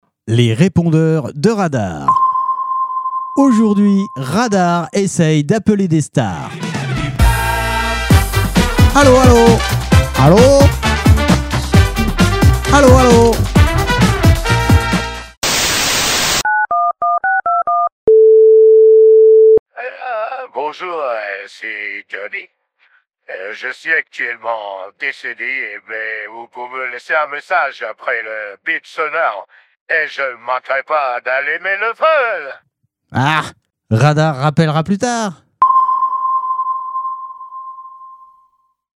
Répondeur Johnny Hallyday du 07.03.2024
Les répondeurs de Radar parodies répondeurs stars radar